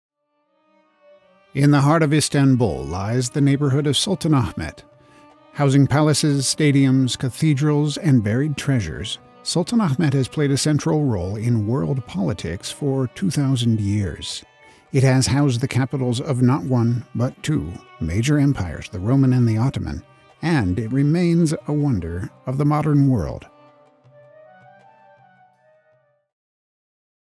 e-learning: tour of Istanbul
I have the energetic voice of a man grateful for the bounty life offers, the gravelly voice of a lifelong outdoorsman, the deep voice of a man who has raised children, the generous voice of a teacher who has forgiven thousands of students, and most of all the ironic voice of a man who has forgiven himself for countless stumbles.